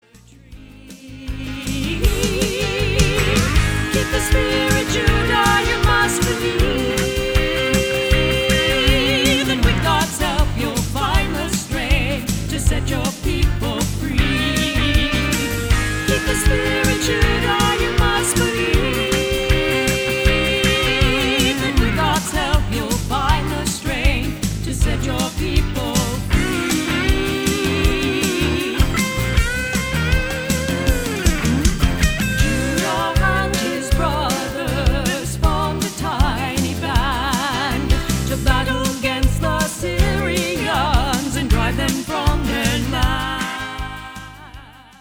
Inspirational music